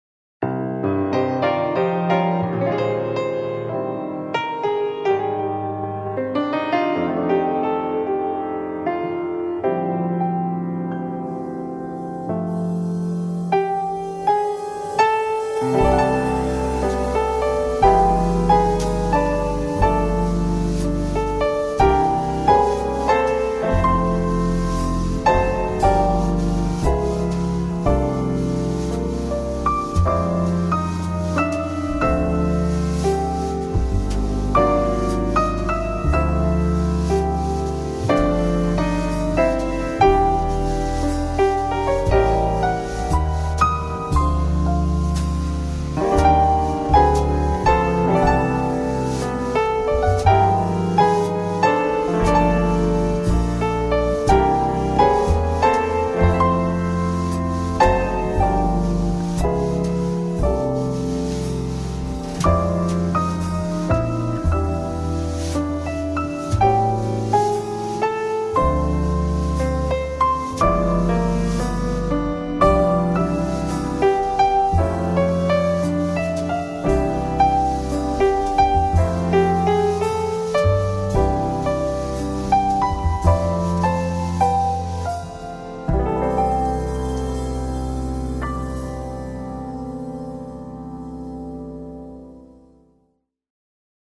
Samples of Jazz Playing & Arranging
Trios
(ballad)